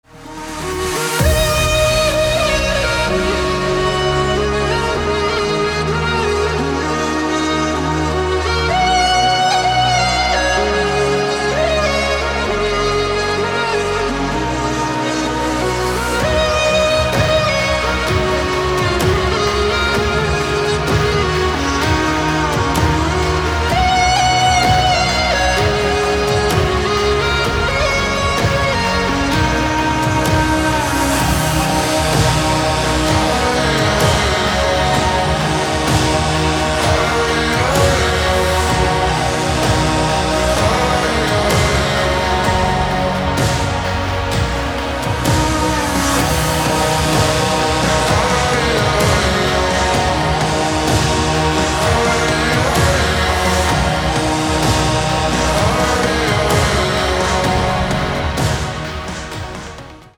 • Качество: 256, Stereo
мужской голос
громкие
Electronic
без слов
Trance
духовые
этнические
Курай